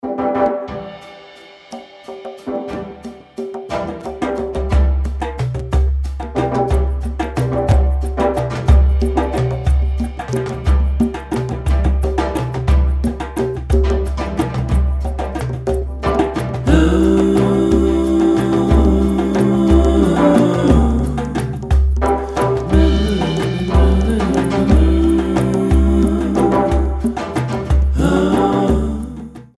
three beats